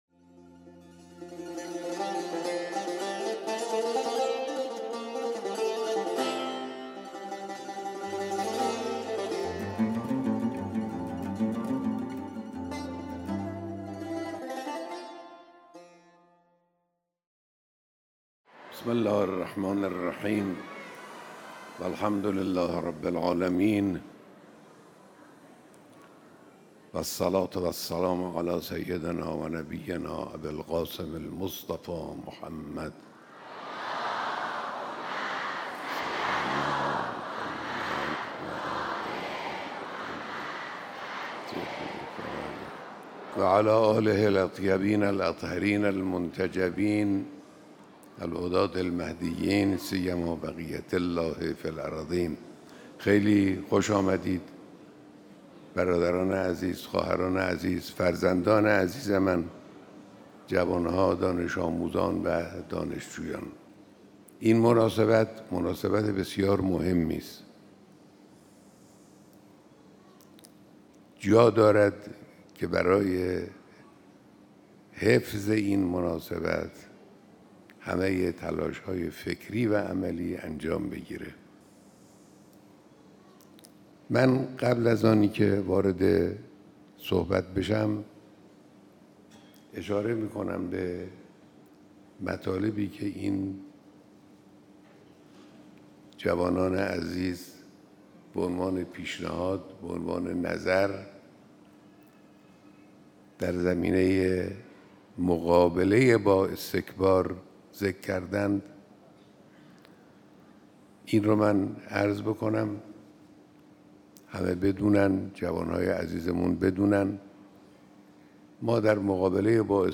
بیانات در دیدار هزاران نفر از دانش‌آموزان و دانشجویان